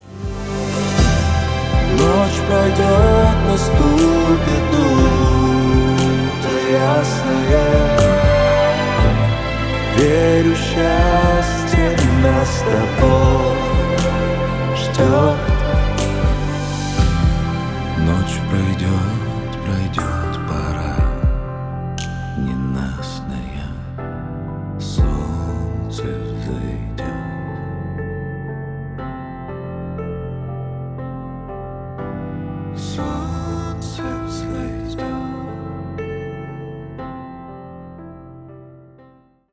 Поп Музыка
кавер
спокойные